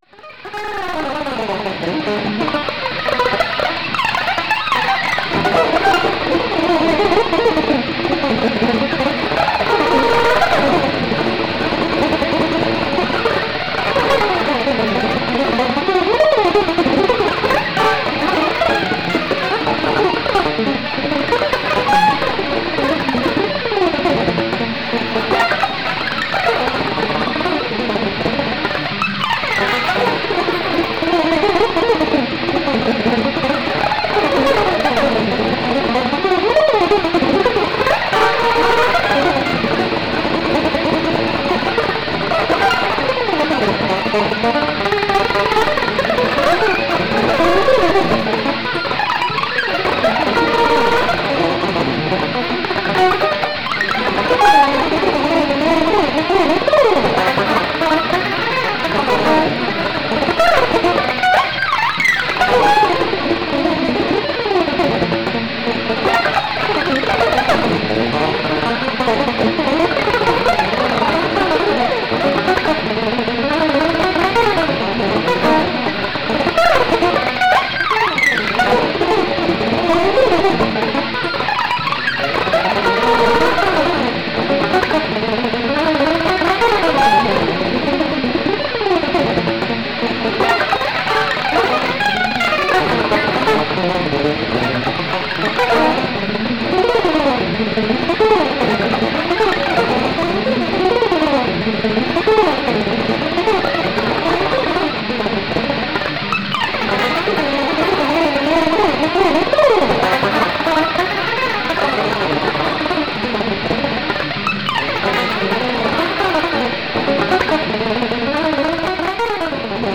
, orchestrated Glossolalia.
A large amount of automatism melodies
which was given out from the ultra high-speed guitar playing
is edited, arranged and unified by computer.